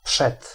When preceded by a voiceless consonant (⟨ch, k, p, t⟩) or end of a word, ⟨rz⟩ devoices to [ʂ], as in przed /ˈpʂɛt/